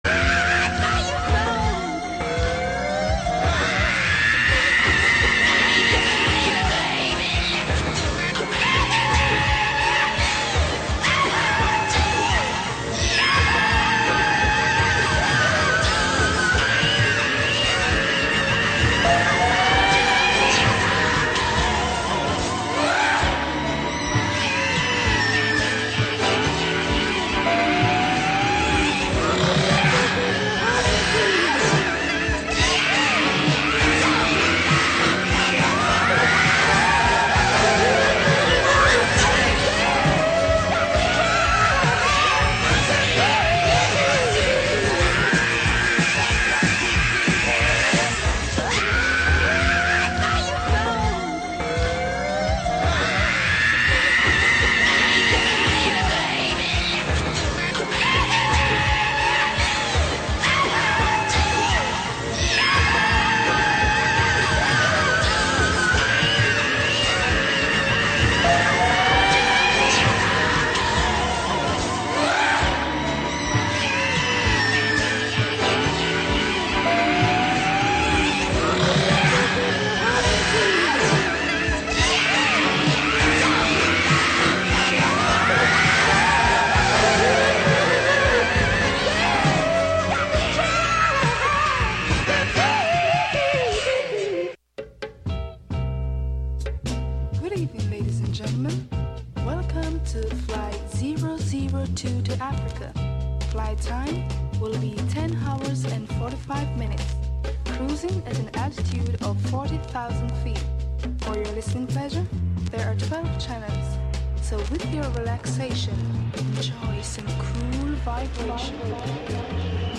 11am Live from Brooklyn, New York
Radio Wonderland abstracts live FM radio with laptop, electrified shoes hit with sticks, and a computer-hacked steering wheel (from a Buick 6).